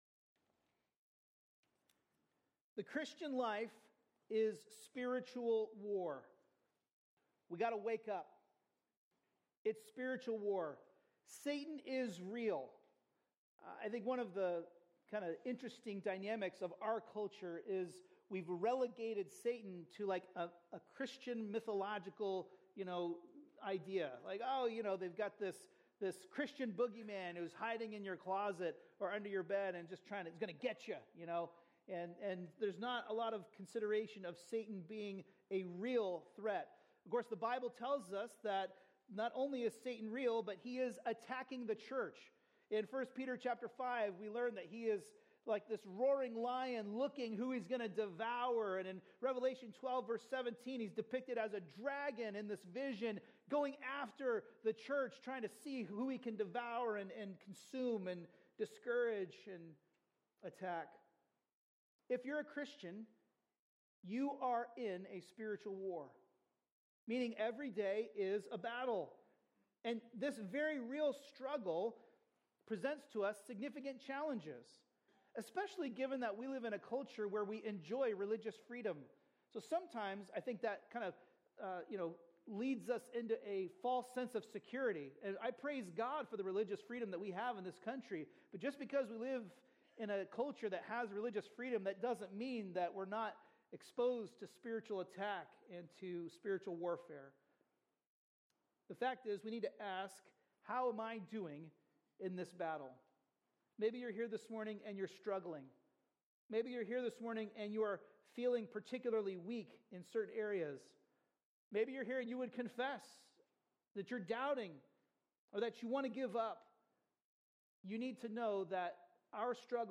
A message from the series "Ezra/Nehemiah." In Nehemiah 1:1-11, we learn that God's restoration is complete restoration.